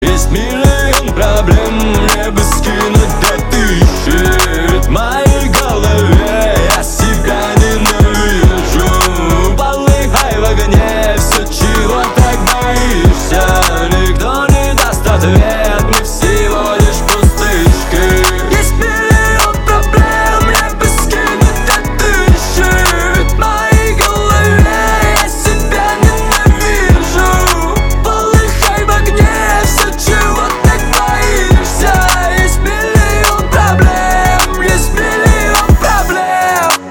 русский рэп , грустные